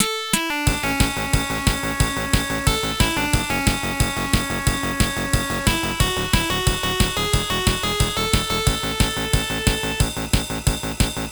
in the forest goofy kitters song (OPNA version)